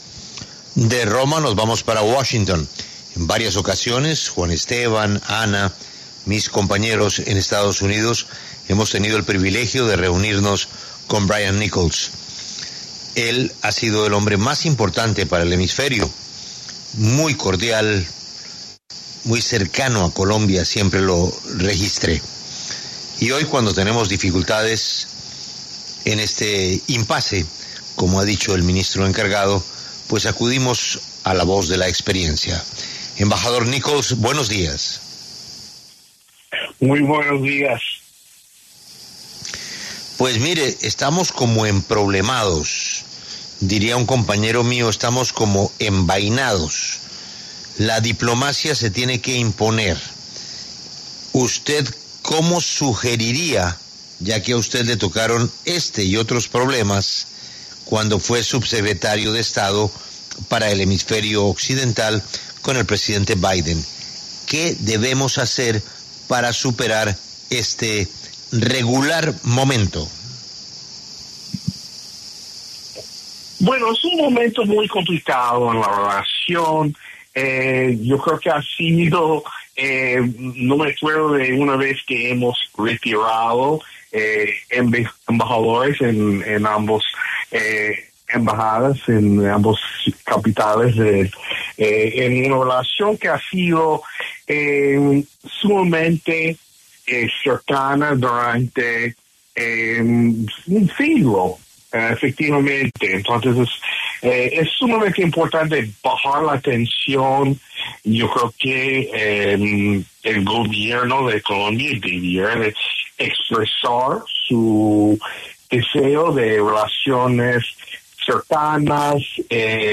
Brian Nichols, exsubsecretario de Estado para Asuntos del Hemisferio Occidental durante el gobierno Biden, conversó con La W acerca de las tensiones diplomáticas entre Colombia y Estados Unidos.